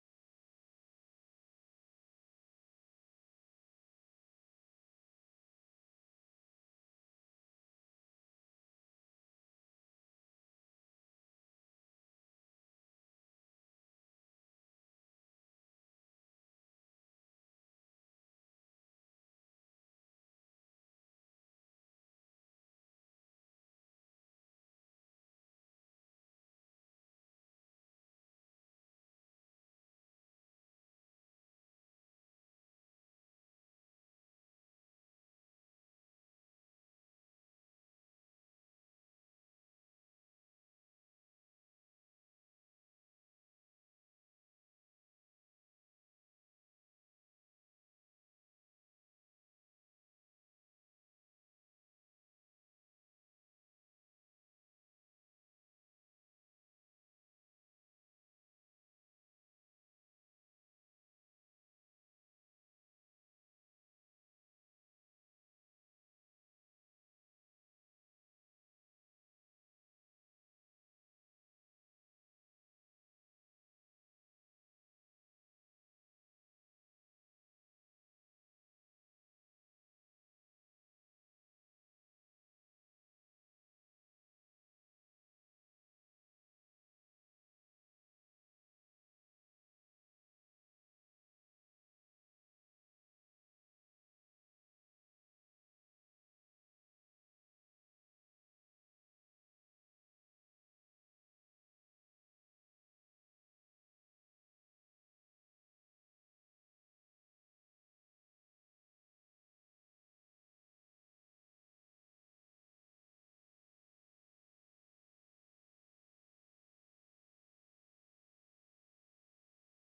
De beelden worden ondersteund door een voice-over.